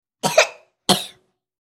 Звуки кашля ребенка
Звук кашляющего ребенка, подавившегося едой